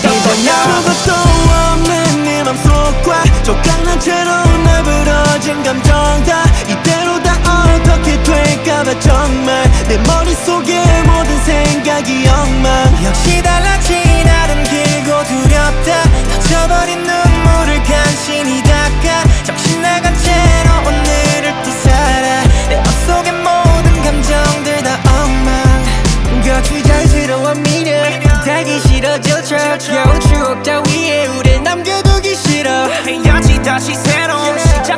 K-Pop Pop
2025-08-22 Жанр: Поп музыка Длительность